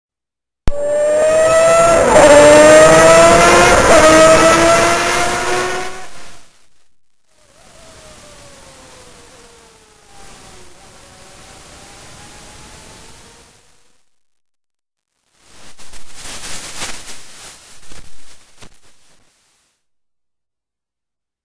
IWAYA ENGINE SOUND COLLECTION